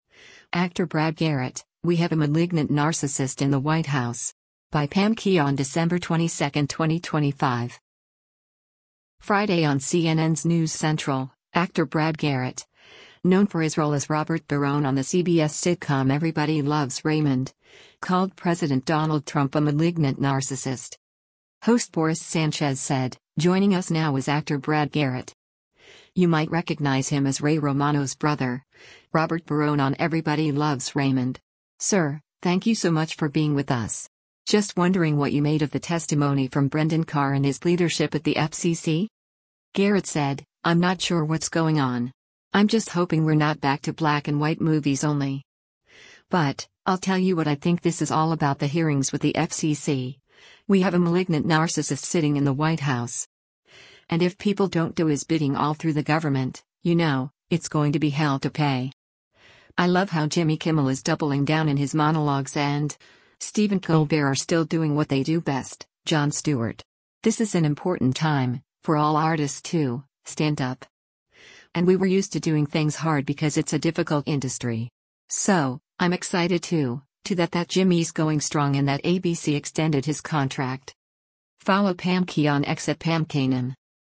Friday on CNN’s “News Central,” actor Brad Garrett, known for his role as Robert Barone on the CBS sitcom “Everybody Loves Raymond,” called President Donald Trump a “malignant narcissist.”